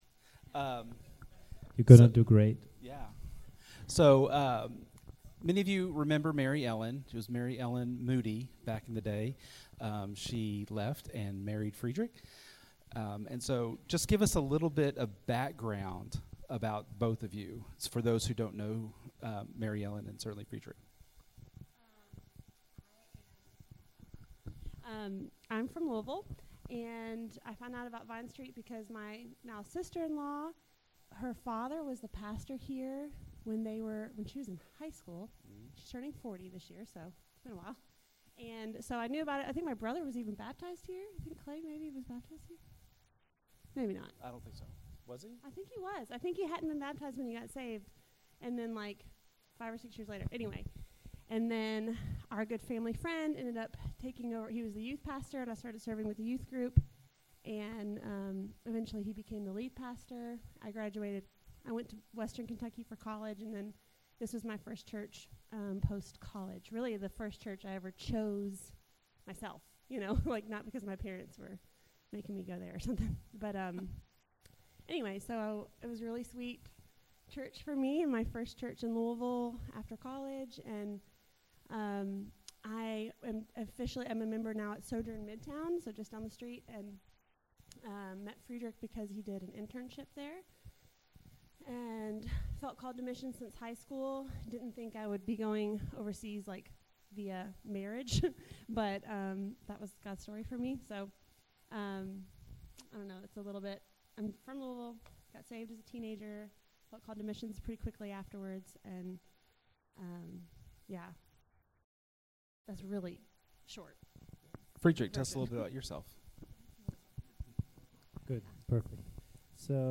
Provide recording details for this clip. Because the weather was a concern for some members, we wanted to post the interview portion of the service so everyone could hear about the work they are going to be doing soon.